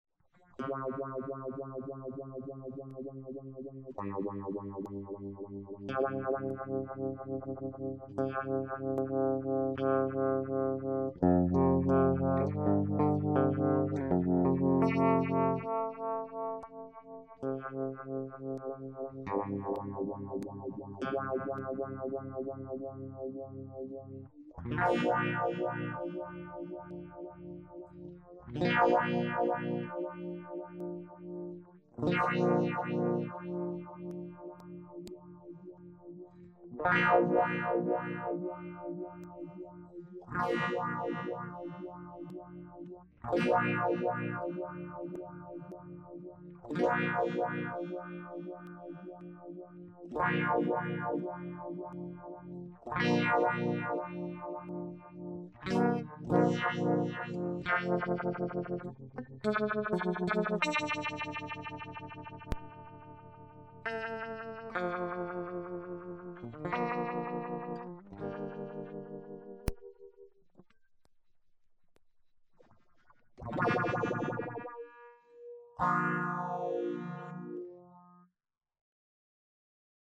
Enregistrer directement dans une table yamaha MG-12/4, avec une carte ECHO MIA MIDI.
Le son est neutre,sans traitement.
UNVIBE: Mods condensateurs :